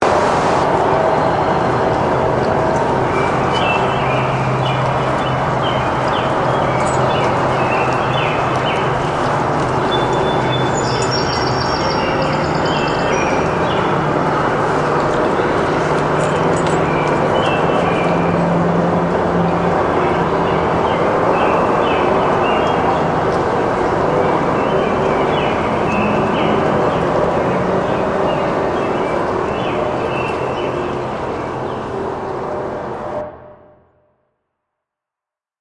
描述：有些鸟（w and和乌鸦？）在纽卡斯尔市中心凌晨4点录制。
标签： 鸟鸣声 黎明 凌晨4点 城市中心 现场录音的英国 春天 自然 现场记录
声道立体声